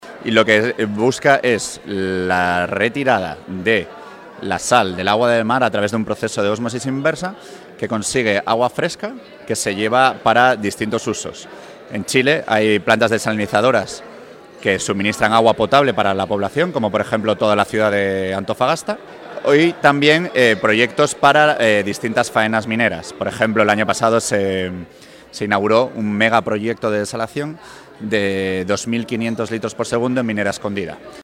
En el contexto del desarrollo de la segunda jornada de APEC 2019. Semana Minera en Atacama